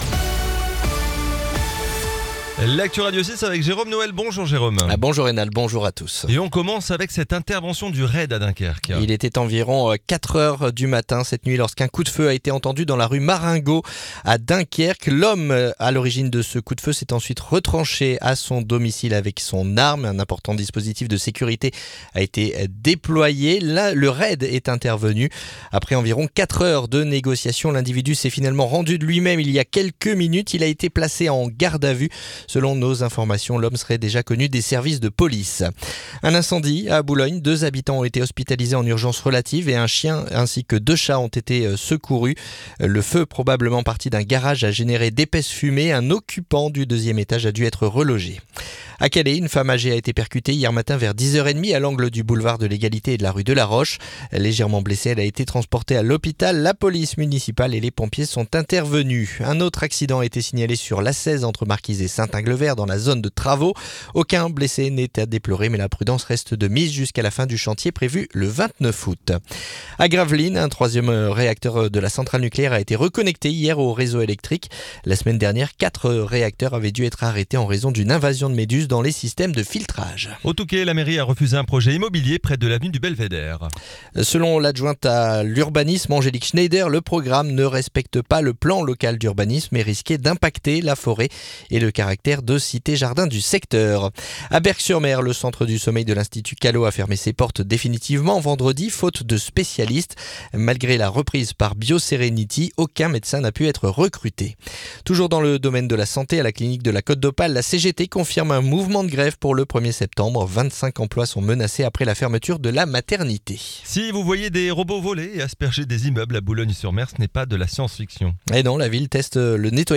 Le journal du jeudi 21 août